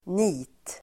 Uttal: [ni:t]